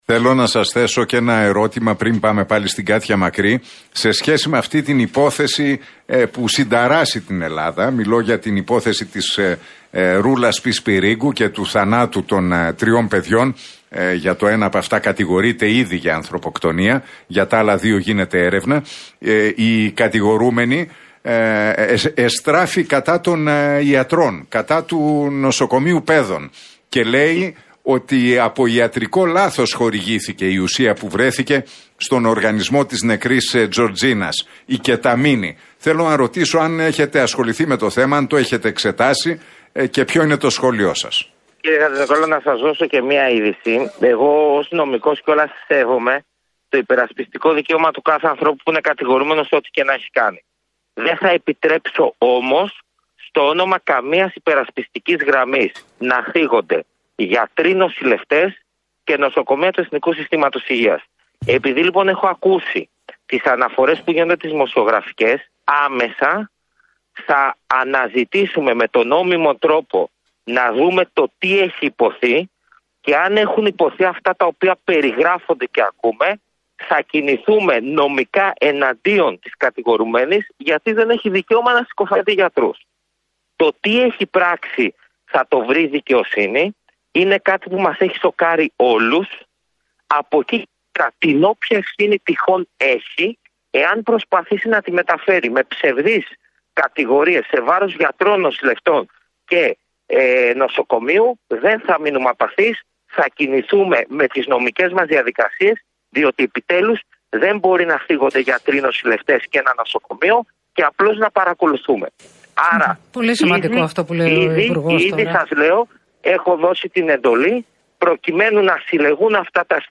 μιλώντας στον Realfm 97,8